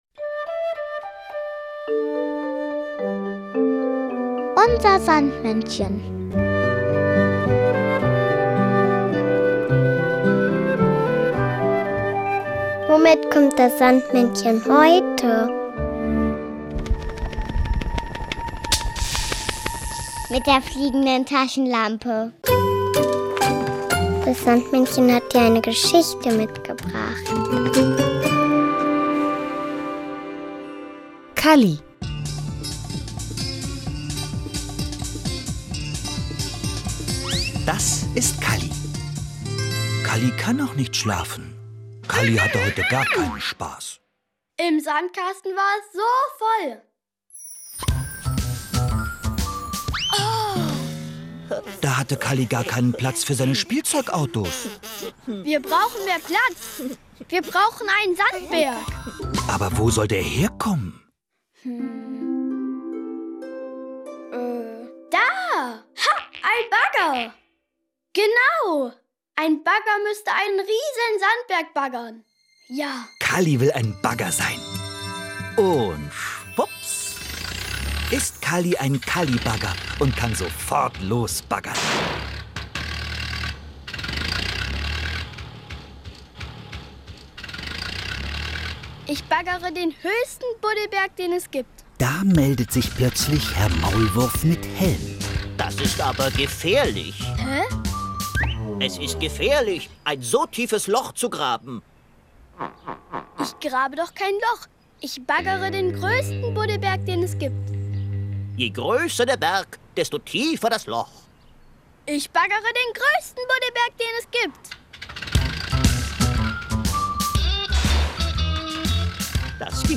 noch das Kinderlied "Der Baggersong" von Helgen.